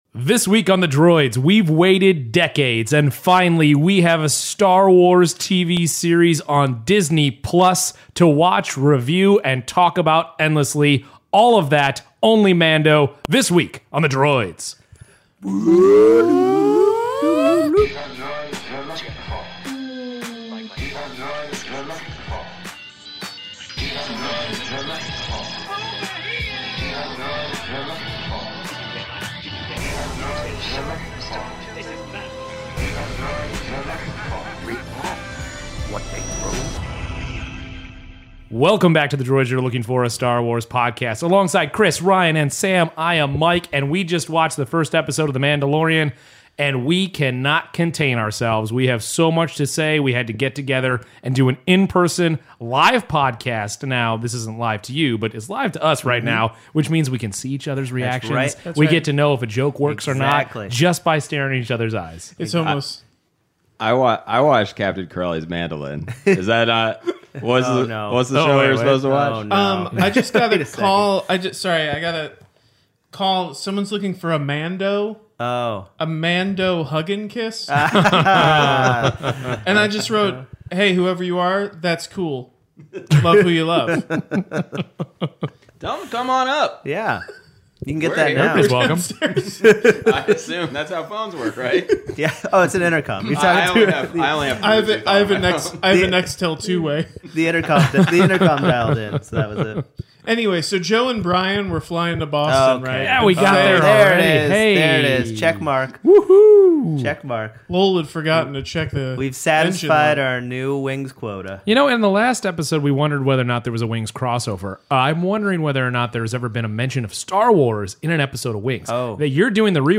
It finally happened, there is a Star Wars TV series and it debuted to the world this week on Disney Plus. “The Mandalorian” episode one made it’s long awaited premiere on Tuesday and the Droids got together in person to talk only about the Mando and of course…Wings.